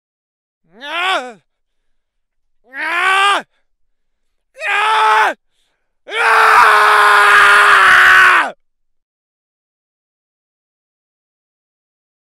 Suara Orang Marah
Kategori: Suara manusia
Keterangan: Efek suara orang marah yang viral bisa menambah keseruan edit video.
suara-orang-marah-id-www_tiengdong_com.mp3